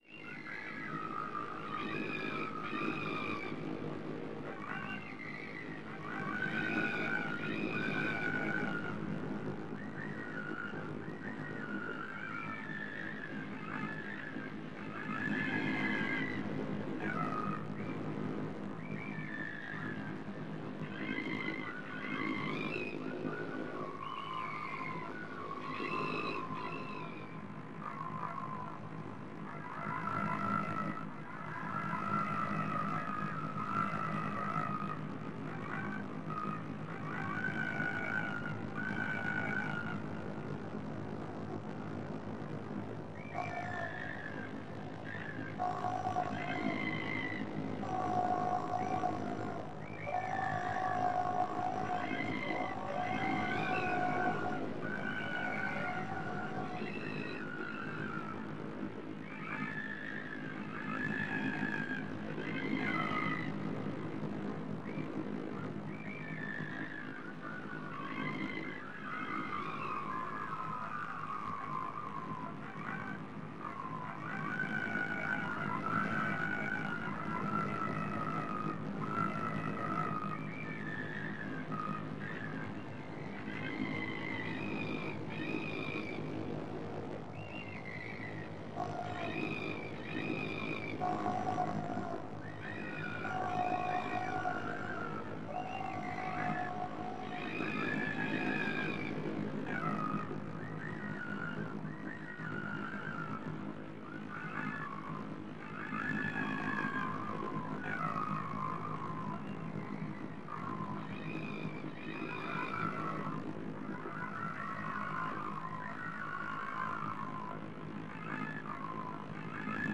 Further, processing the sound of gulls (recorded in Venice) by granular synthesis and randomly move them around the audience creates an immersive sonic atmosphere. Sound spatialization is realized by using the IEM's ambisonic approach.
Audio Example: Granular on flying gulls
10_Doves_Granular.mp3